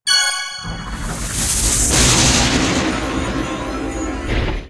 whirlpoolStart.wav